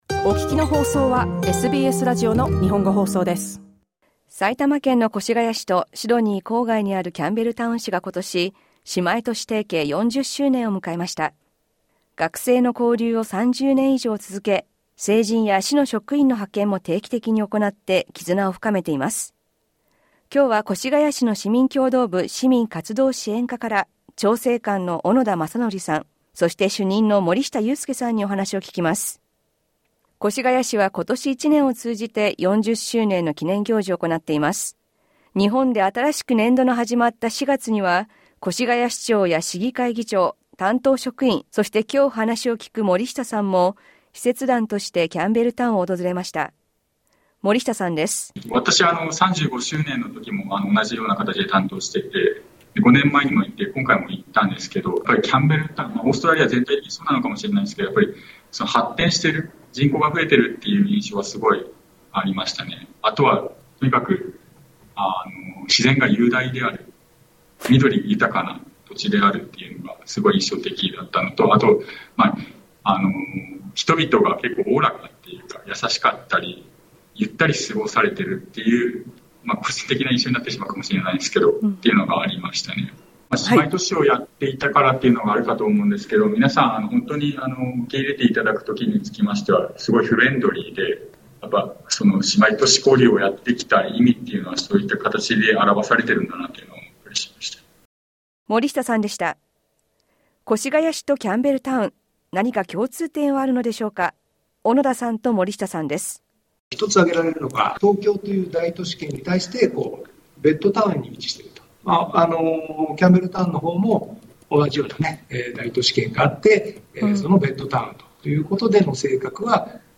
インタビューで聞きました。